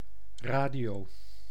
Ääntäminen
RP : IPA : /ˈɹeɪdiˌəʊ/ GenAm: IPA : /ˈɹeɪdiˌoʊ/